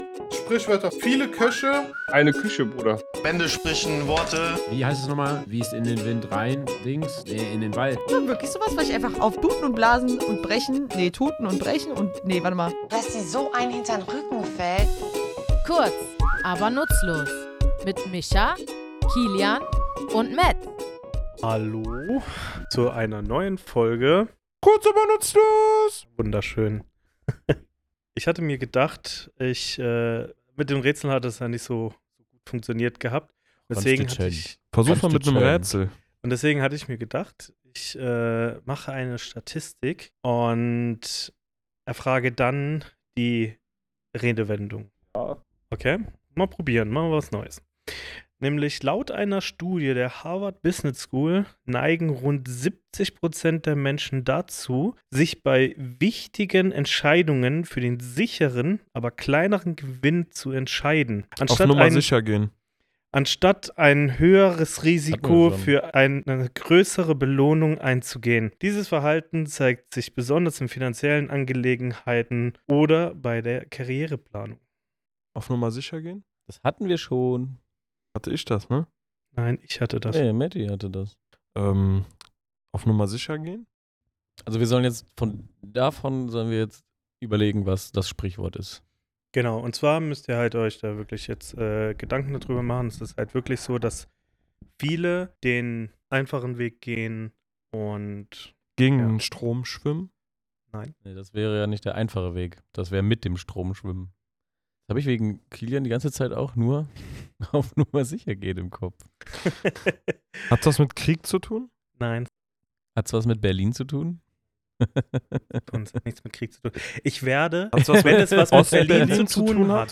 Wir, drei tätowierende Sprachliebhaber, nehmen euch in unserem Tattoostudio mit auf eine sprachliche Reise über Bescheidenheit, Sicherheit und die Kunst, das Greifbare zu schätzen.